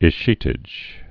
(ĭs-chētĭj)